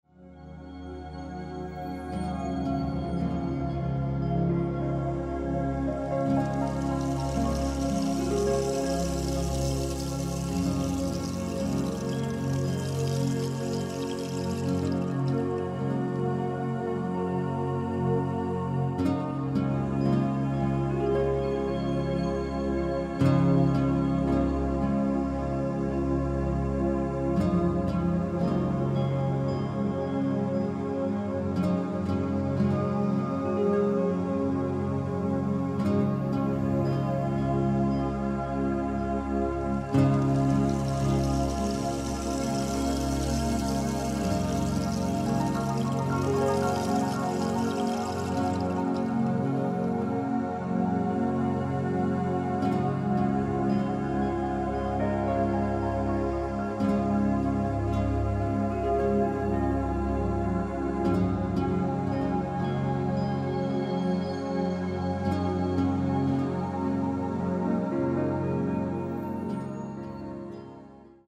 Reiner Klang   11:06 min